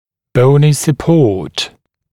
[‘bəunɪ sə’pɔːt][‘боуни сэ’по:т]поддерживающая костная структура, костная поддержка, оруажющая коть